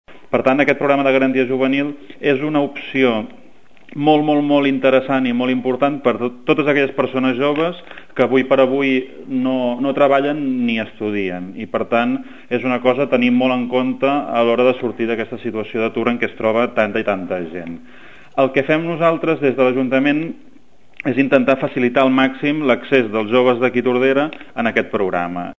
Es tracta de més de 350 places, distribuïdes en 13 programes d’actuació, que pretenen donar resposta a un ampli ventall de necessitats dels joves que volen incorporar-se al mercat laboral. Marc Unió és el regidor de Promoció econòmica de l’ajuntament de Tordera.